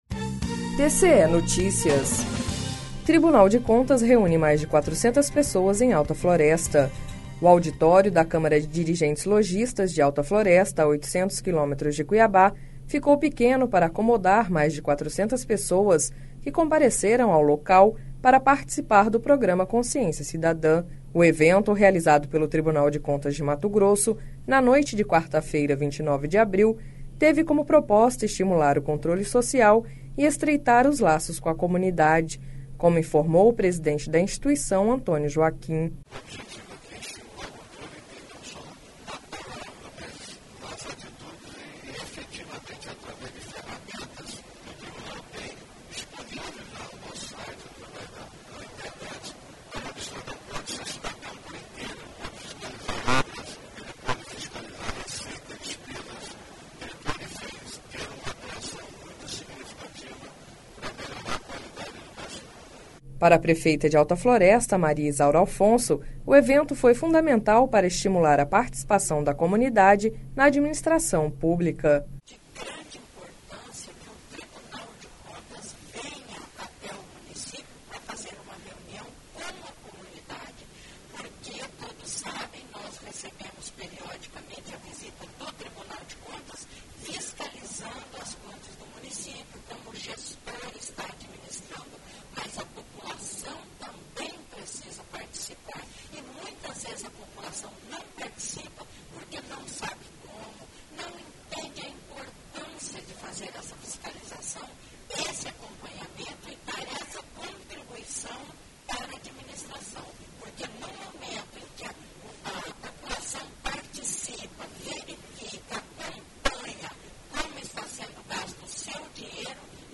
Sonora: Antonio Joaquim – conselheiro presidente do TCE-MT
Sonora: Maria Izaura Alfonso – prefeita de Alta Floresta
Sonora: Valter Albano – conselheiro corregedor geral do TCE-MT